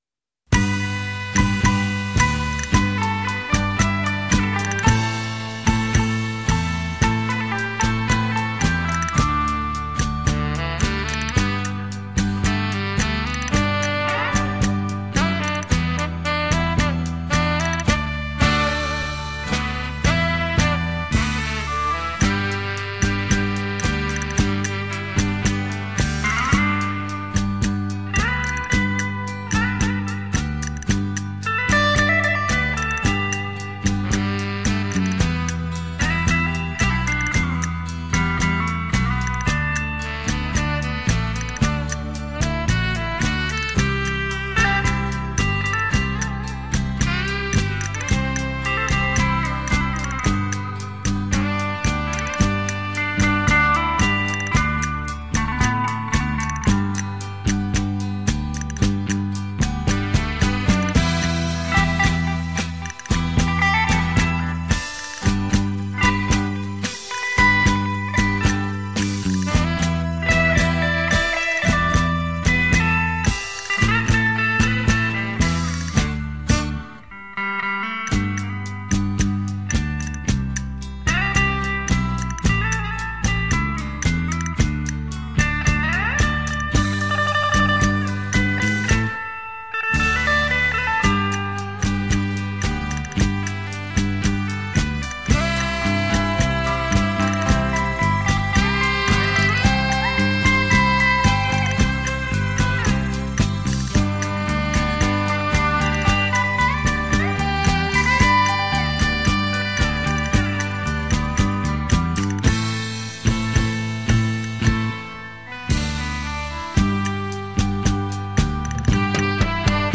吉他主演乐队加盟，再奏名歌旋律新曲。